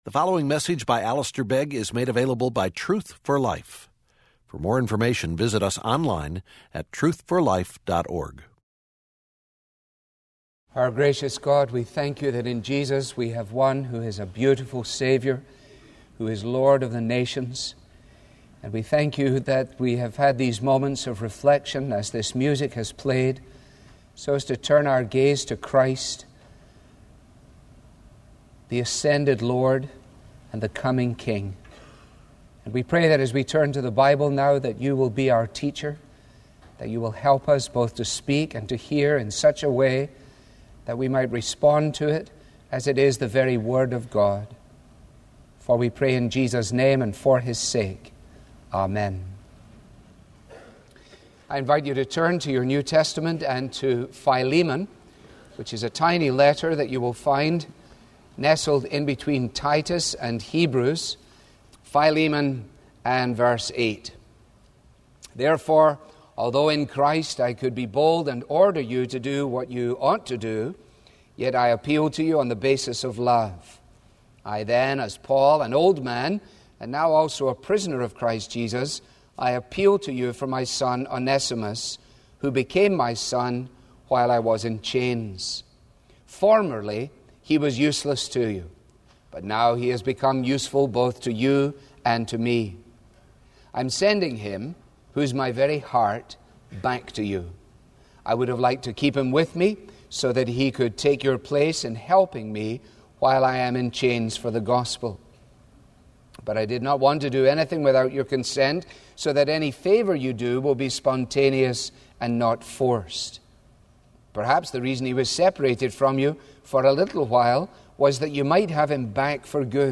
Sermons on union-with-christ from Philemon with a transcript from Truth For Life